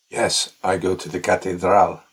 Q&A_11_response_w_accent.mp3